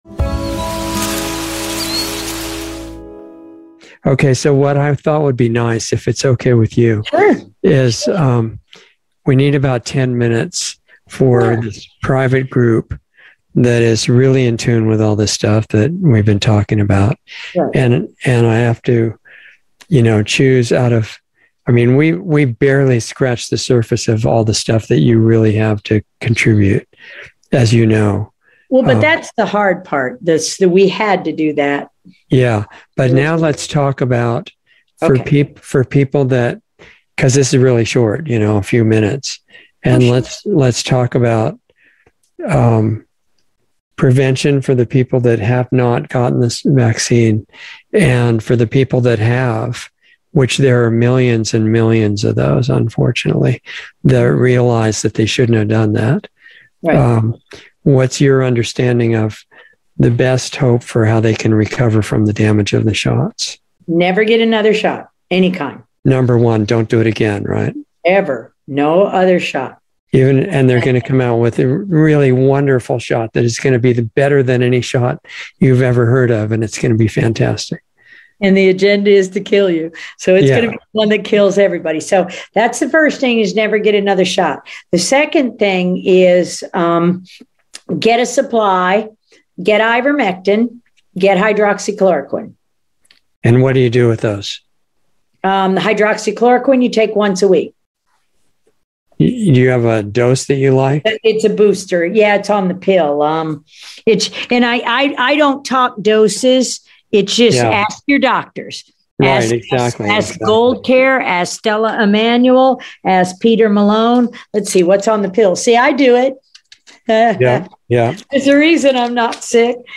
Planetary Healing Club - Dr. Judy Mikovits - Insider Interview 7/21/22